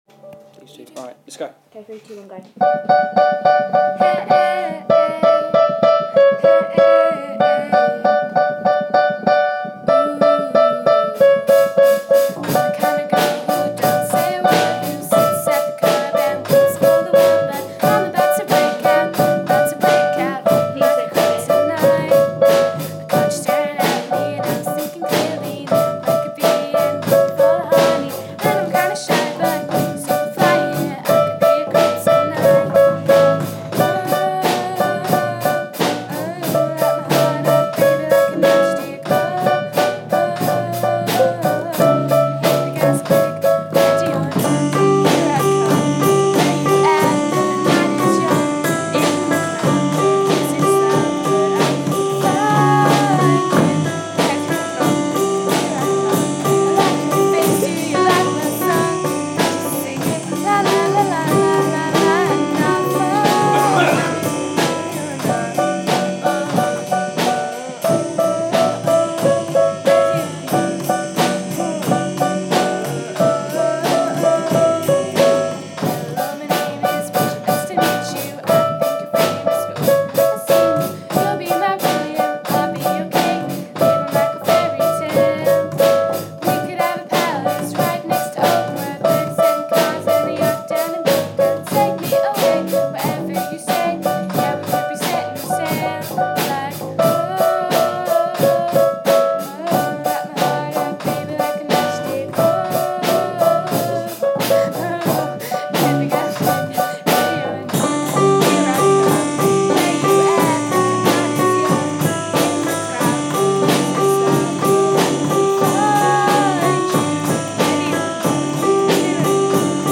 Ready Or Not Performance
An episode by Simon Balle Music